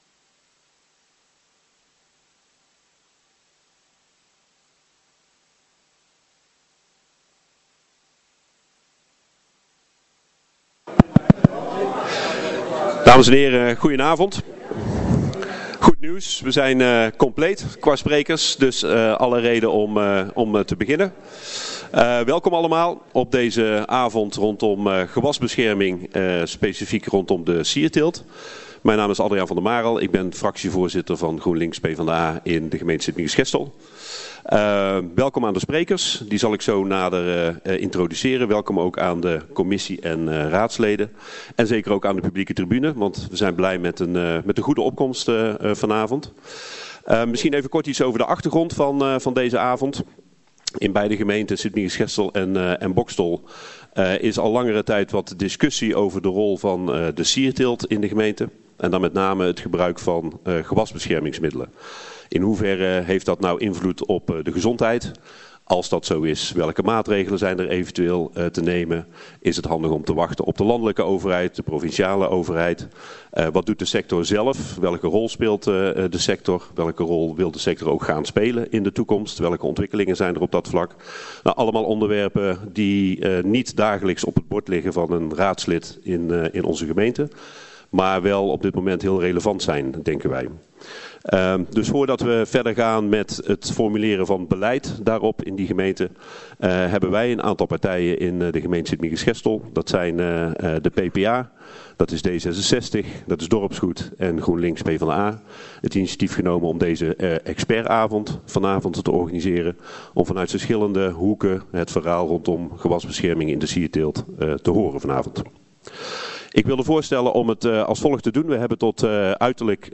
Deze bijeenkomst wordt georganiseerd op initiatief van de fracties D66, Dorpsgoed, PPA en GroenLinks/PvdA van de gemeente Sint‑Michielsgestel (zie uitnodiging). Het is een openbare bijeenkomst.